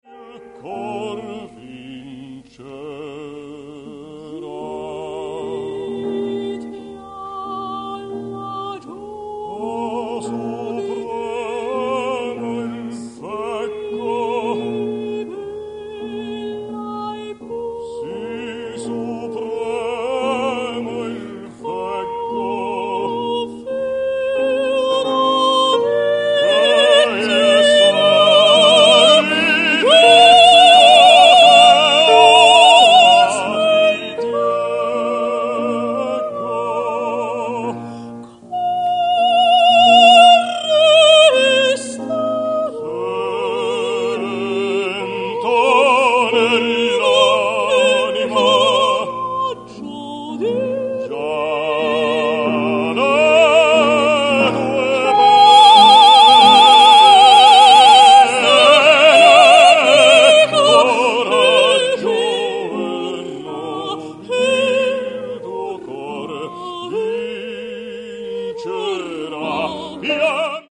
Mara Zampieri - Lyric Soprano
RECITAL
MARA ZAMPIERI AND PIERO CAPPUCCILLI AT LA SCALA
Pianoforte
Teatro alla Scala